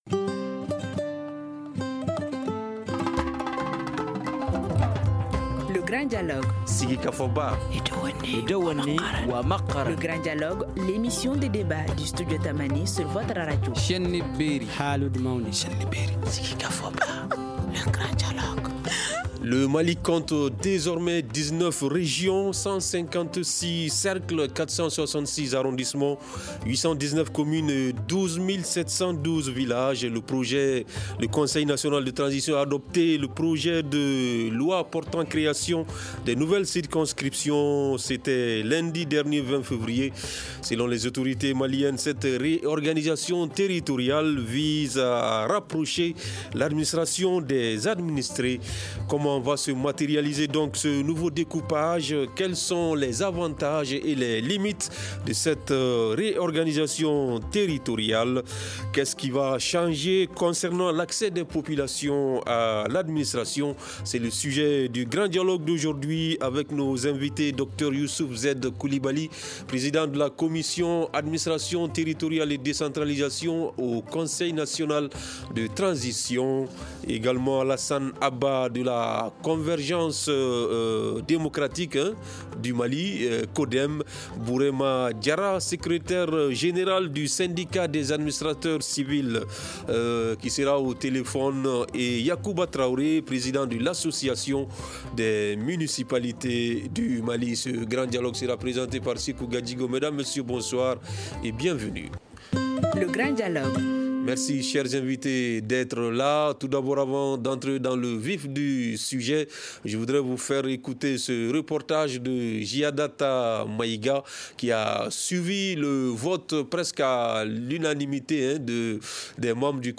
C’est le sujet du Grand dialogue d’aujourd’hui avec nos invités :